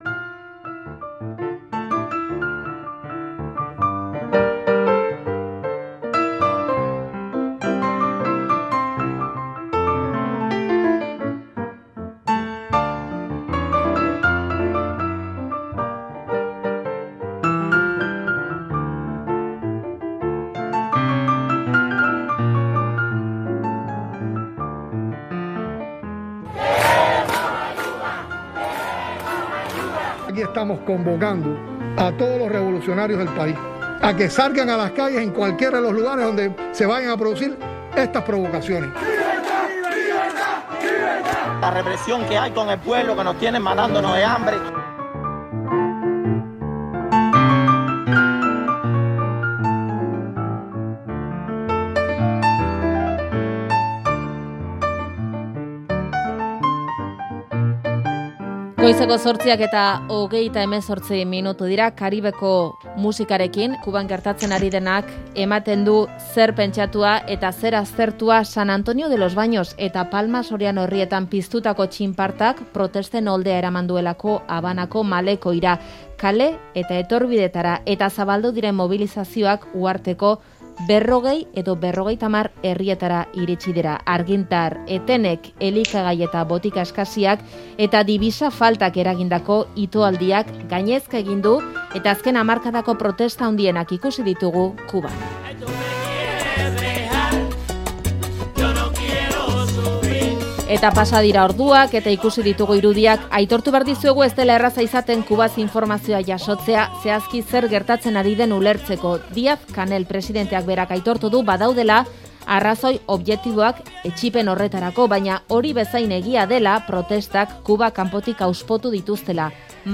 kazetariarekin hitz egin dugu Faktorian. Uhartearen uneko egoerari heldu diogu, gobernuaren aurkako mobiliazioen gakoei eta AEBen jarrerari.